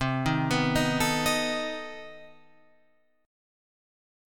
CmM13 Chord
Listen to CmM13 strummed